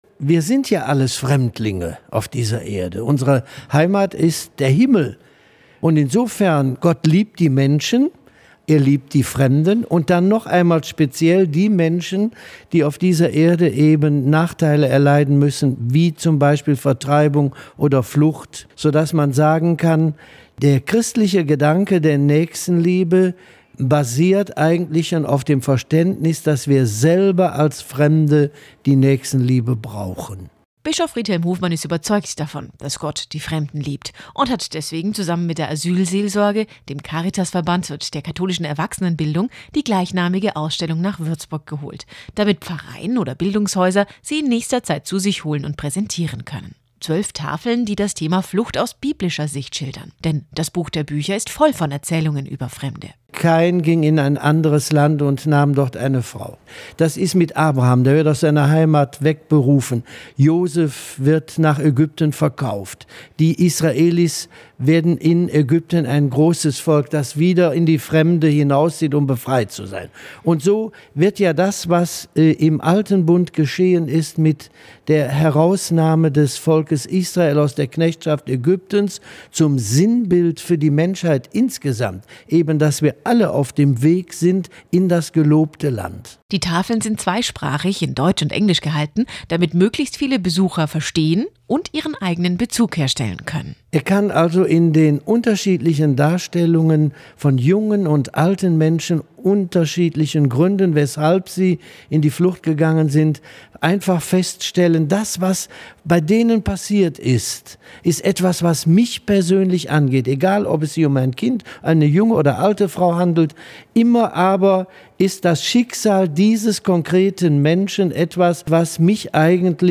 Bischof Friedhelm Hofmann gefragt, weshalb er glaubt, dass Gott die Fremden liebt.
Radiobeitrag